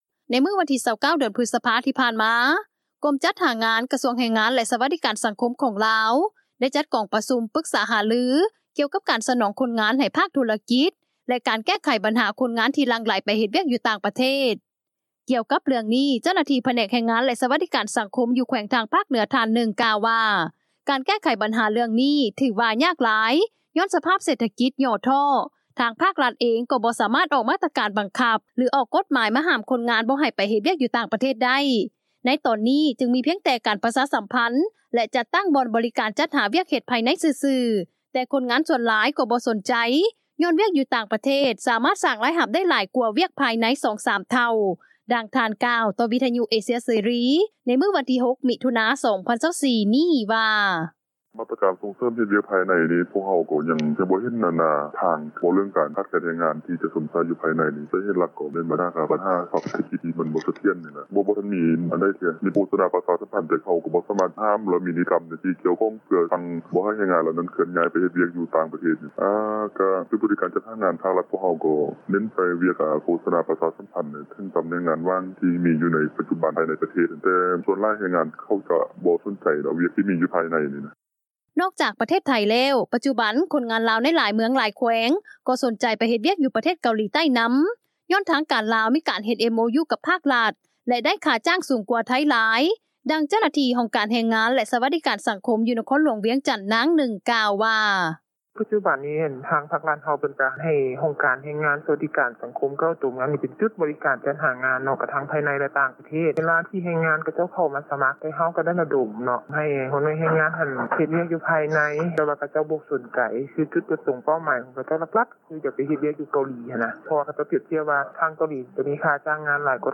ດັ່ງເຈົ້າໜ້າທີ່ ຫ້ອງການແຮງງານ ແລະສະຫວັດດີການສັງຄົມ ຢູ່ນະຄອນຫຼວງວຽງຈັນ ນາງໜຶ່ງ ກ່າວວ່າ: